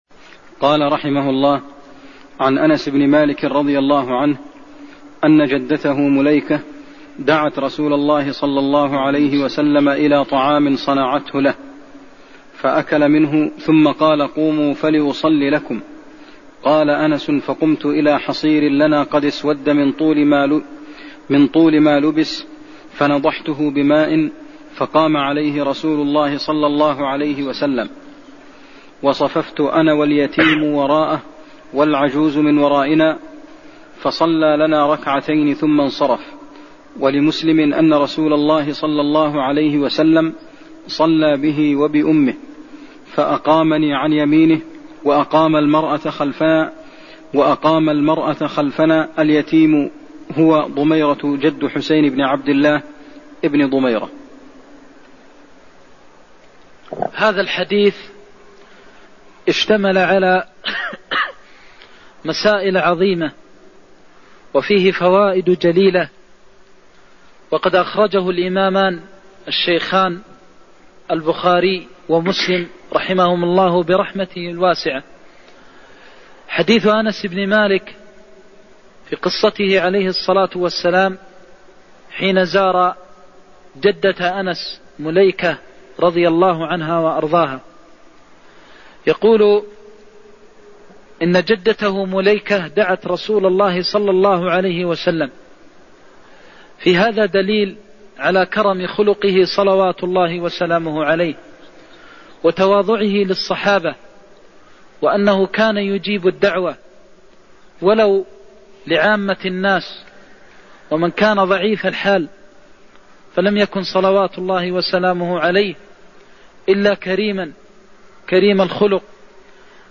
المكان: المسجد النبوي الشيخ: فضيلة الشيخ د. محمد بن محمد المختار فضيلة الشيخ د. محمد بن محمد المختار صففت أنا واليتيم وراءه والعجوز من ورائنا (69)